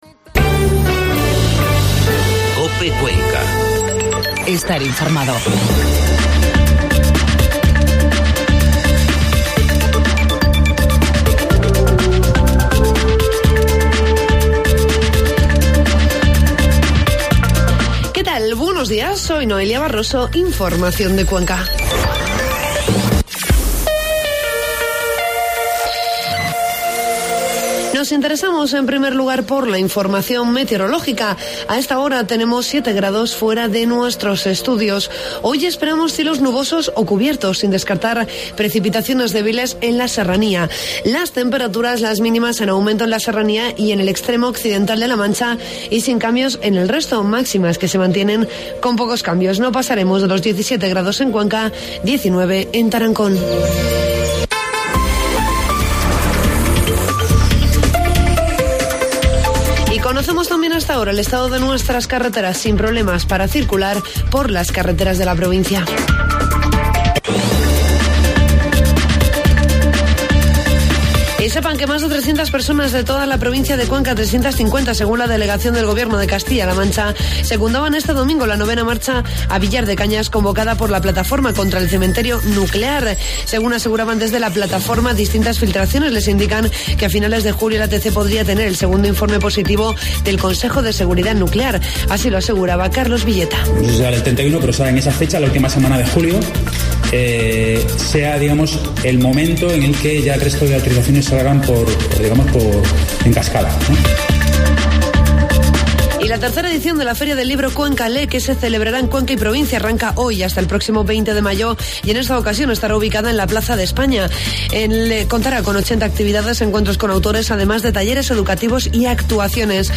Informativo matinal 14 de mayo